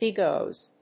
/ˈbigoz(米国英語), ˈbi:gəʊz(英国英語)/